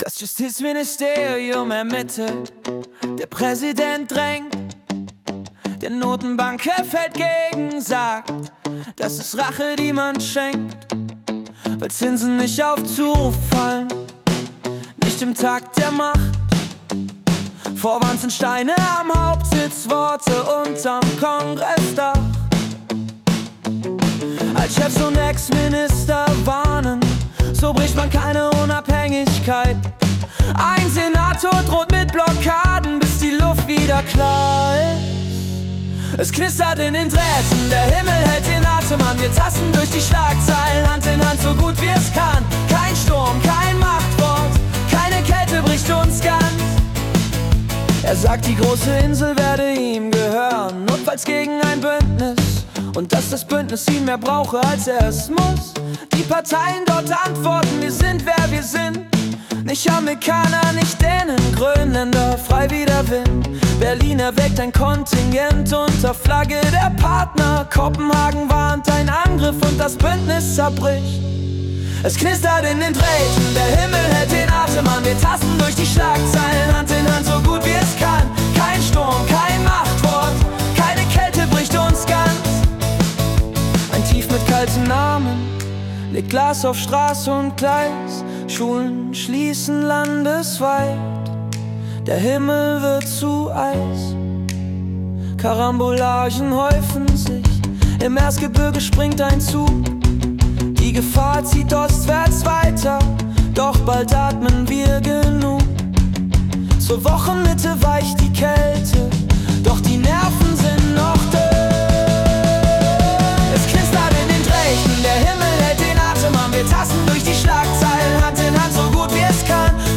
Die Nachrichten vom 13. Januar 2026 als Singer-Songwriter-Song interpretiert.
Jede Folge verwandelt die letzten 24 Stunden weltweiter Ereignisse in eine originale Singer-Songwriter-Komposition.